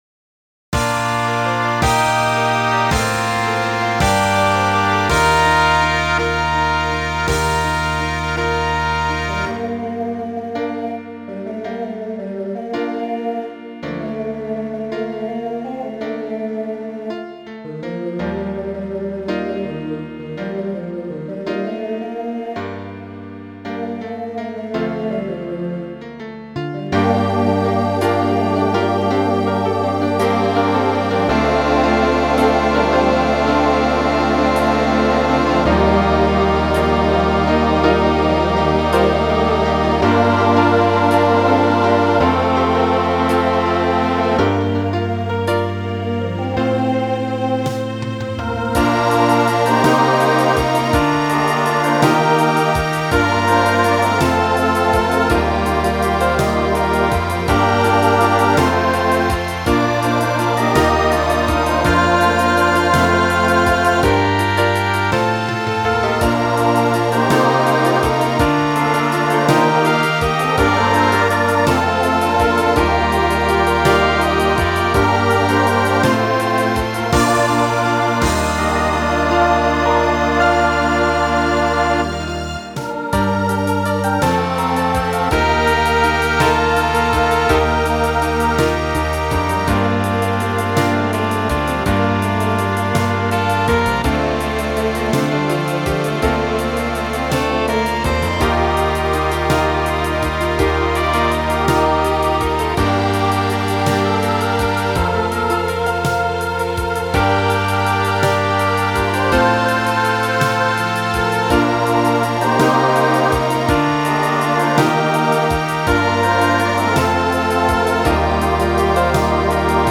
Voicing SATB Instrumental combo Genre Pop/Dance
Ballad